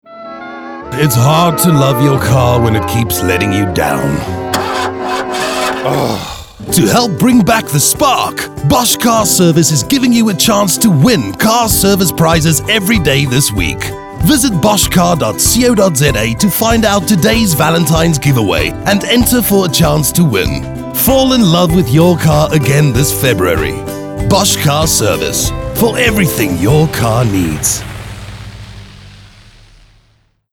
authoritative, Deep, raspy
Bosch Car Service | Friendly